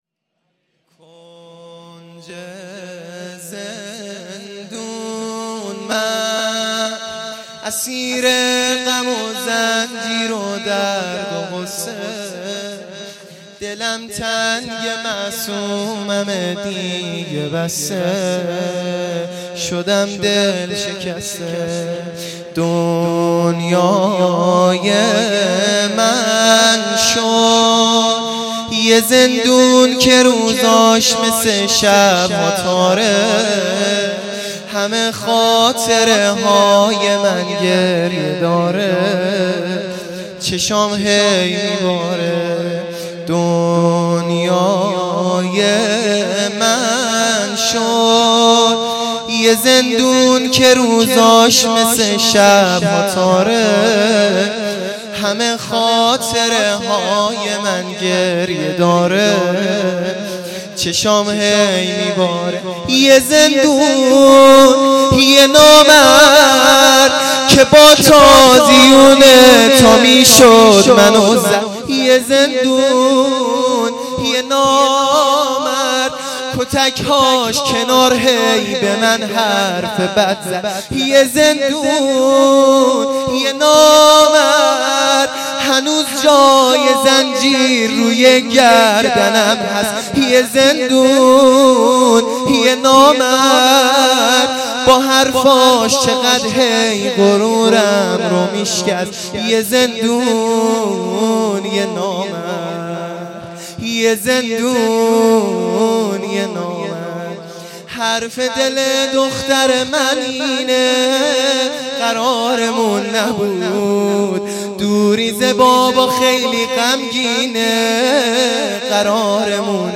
زمینه ا کنج زندون من اسیرم